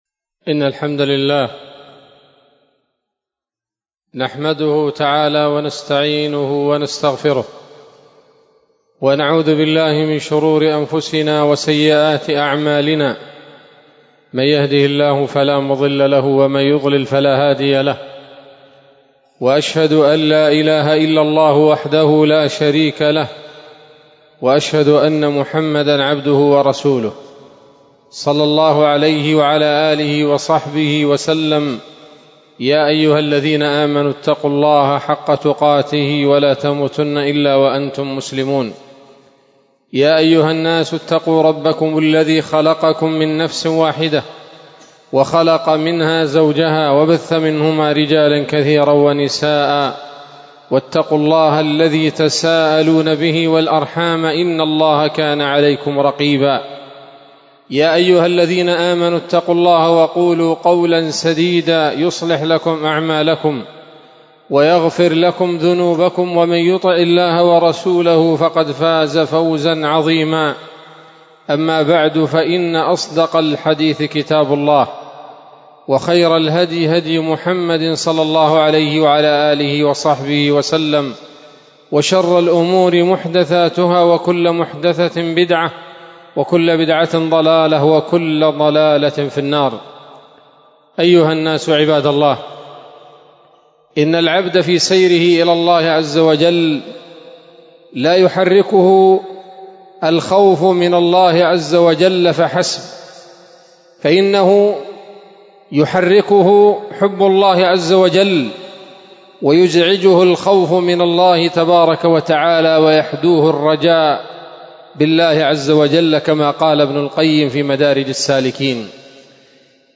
خطبة جمعة بعنوان: (( الرجاء وحسن العمل )) 25 ربيع آخر1447 هـ، دار الحديث السلفية بصلاح الدين